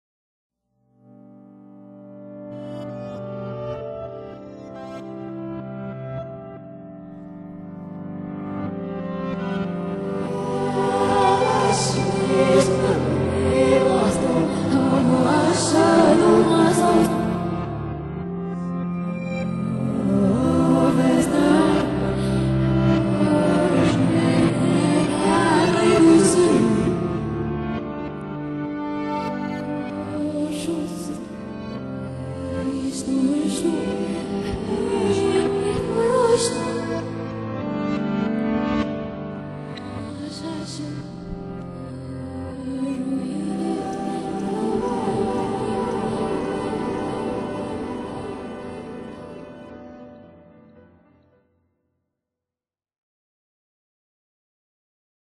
产生了这首有天使之音感觉的专辑序曲